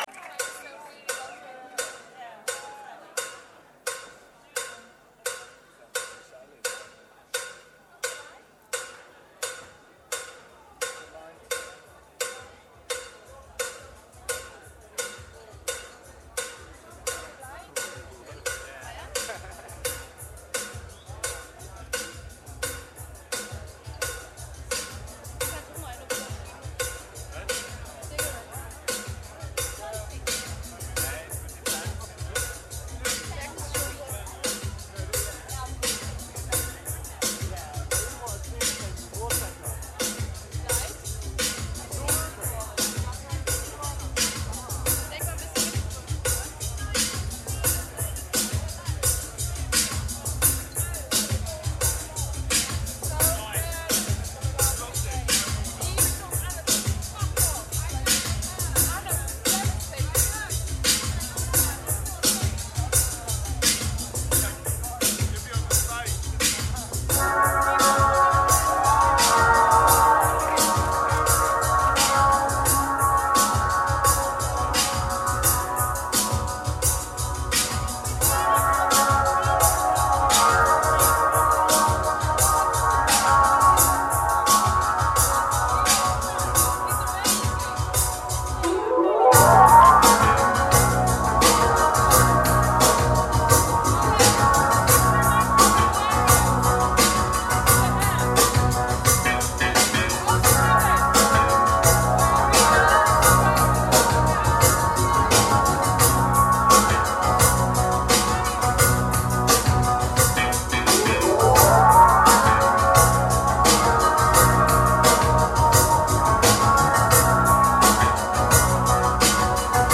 venue The Old Truman Brewery